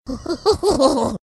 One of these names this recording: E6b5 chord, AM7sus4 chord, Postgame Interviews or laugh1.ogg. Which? laugh1.ogg